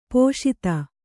♪ pōṣita